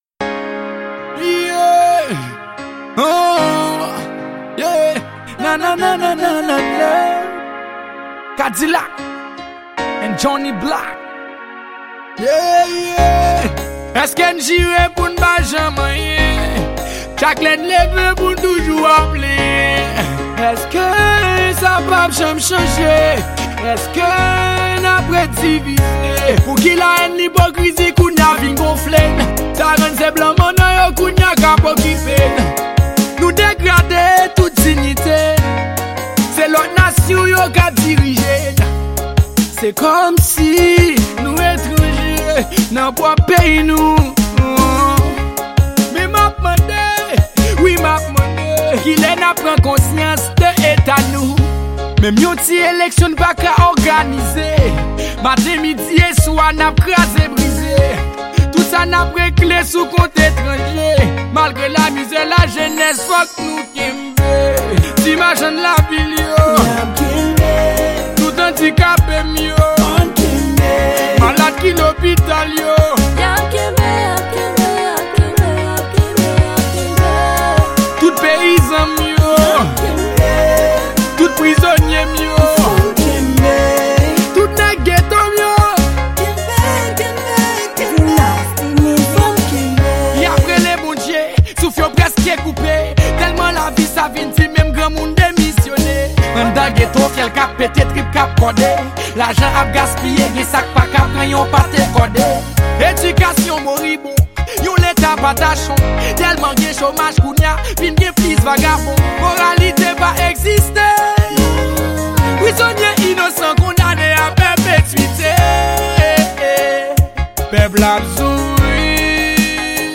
Genre: Raggae.